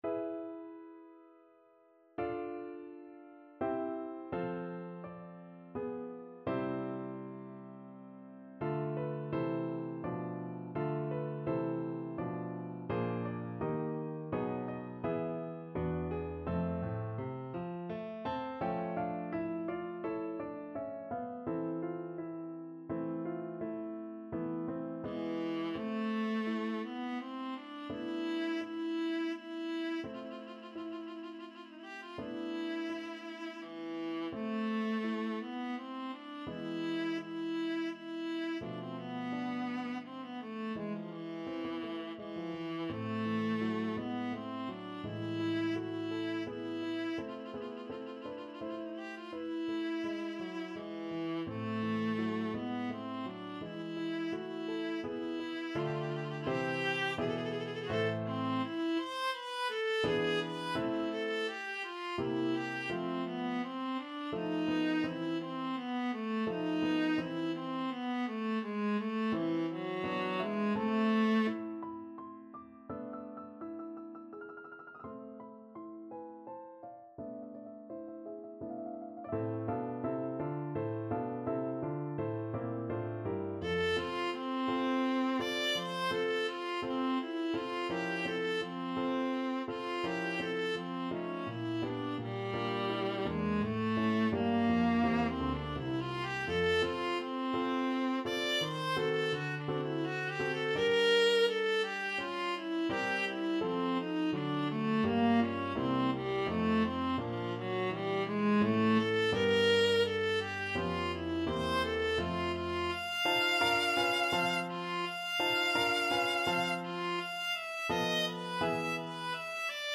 Free Sheet music for Viola
3/4 (View more 3/4 Music)
A minor (Sounding Pitch) (View more A minor Music for Viola )
Andante =84
Classical (View more Classical Viola Music)
tchaikovsky_canzonetta_VLA.mp3